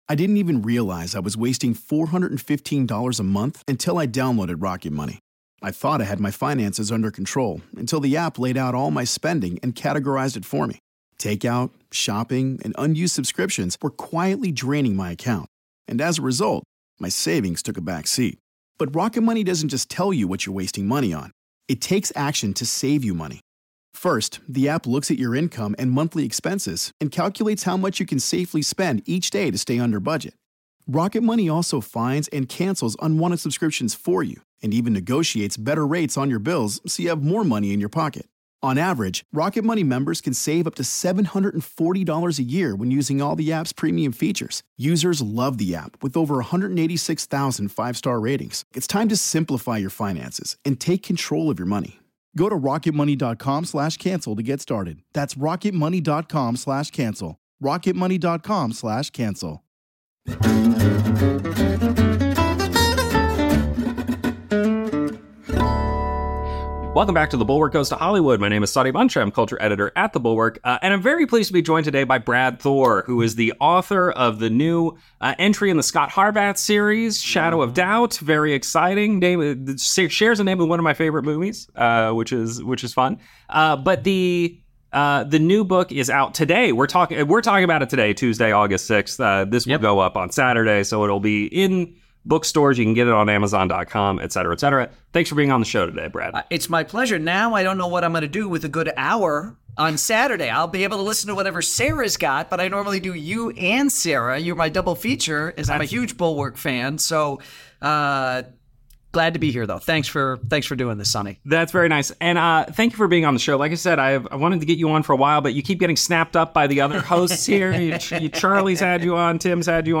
This week I met with novelist (and Bulwark+ member!) Brad Thor in a shadowy location (upstairs at Dallas’s magnificent flagship Half Price Books ) to discuss his latest book of international intrigue, Shadow of Doubt . We also talked about the idea that men don’t buy novels, smuggling real-world ideas into the universe of his fiction while maintaining their entertainment value, and a potential adaptation of his Scot Harvath novels into a streaming hit.